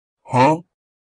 PLAY huh sound effect
huh-meme.mp3